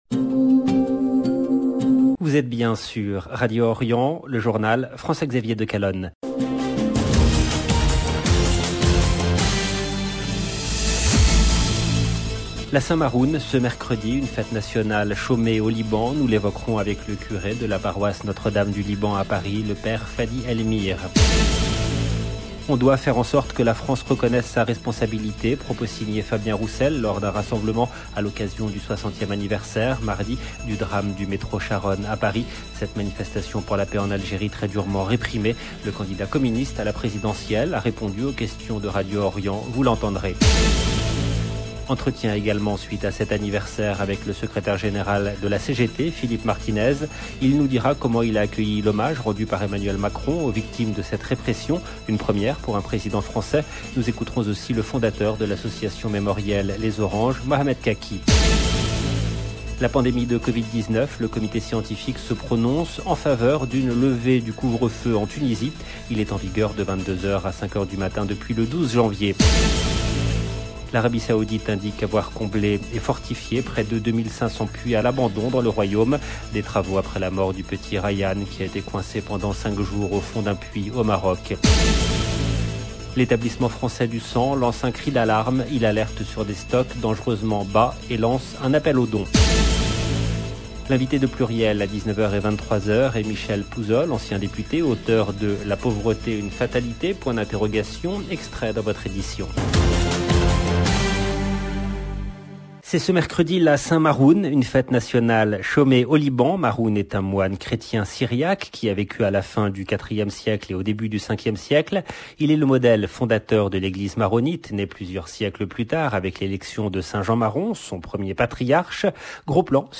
LE JOURNAL DU SOIR EN LANGUE FRANCAISE DU 9/02/22 LB JOURNAL EN LANGUE FRANÇAISE